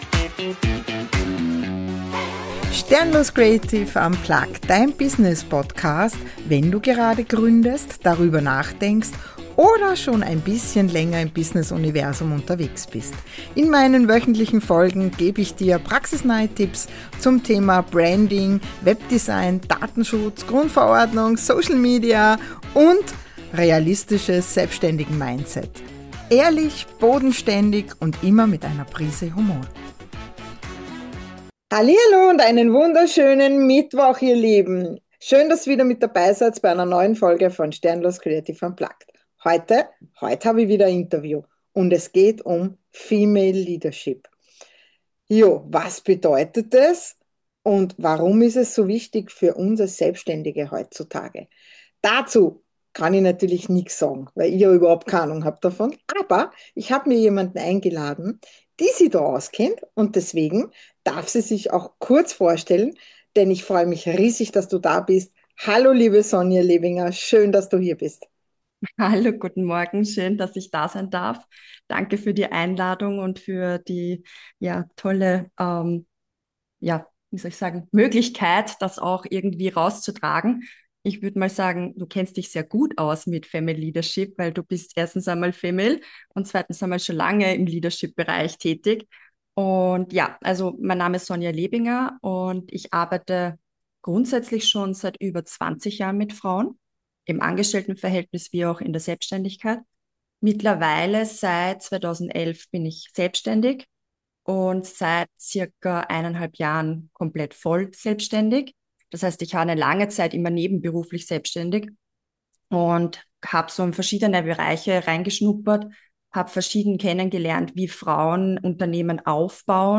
Ein ehrliches Gespräch über Wachstum, Rollenbilder und den Mut, den eigenen Weg zu gehen.